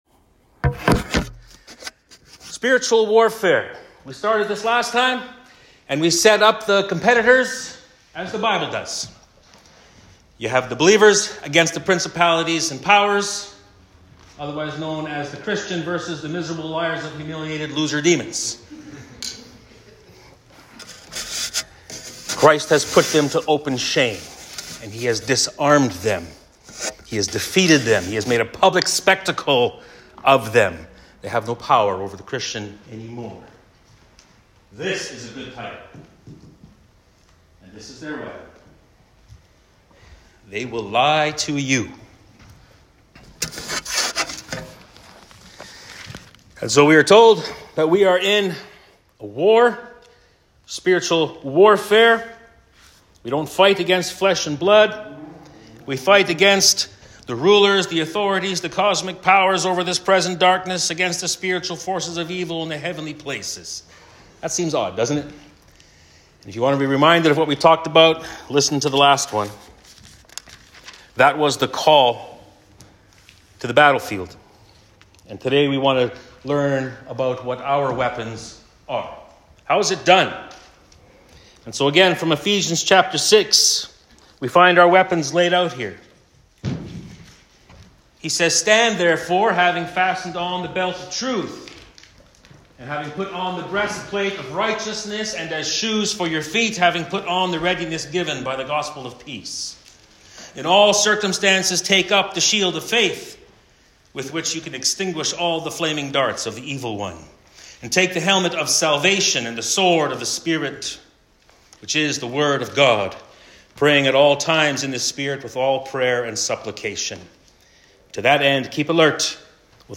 Men's Night Sessions
Current Sermon